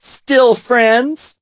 poco_kill_03.ogg